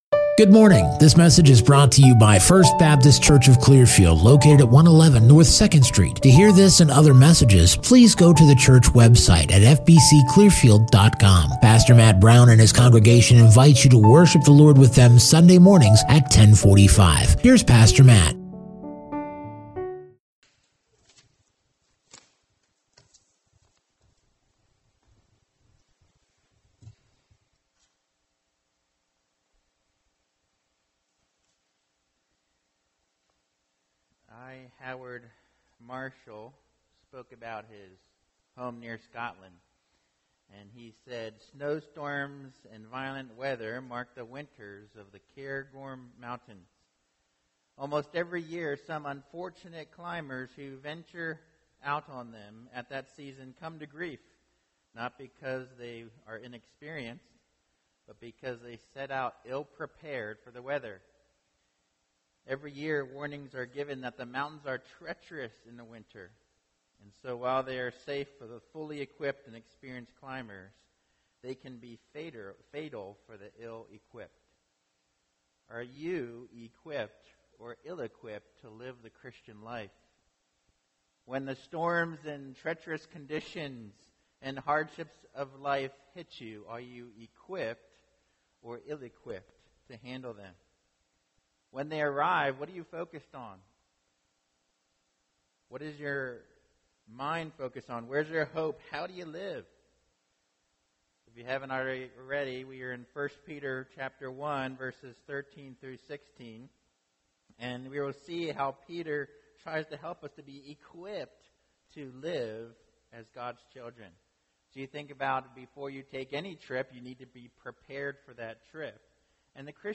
2021 Hope and Holiness Preacher